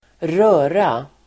Uttal: [²r'ö:ra]